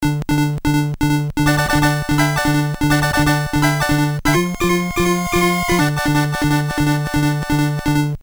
PSG音源の音の例2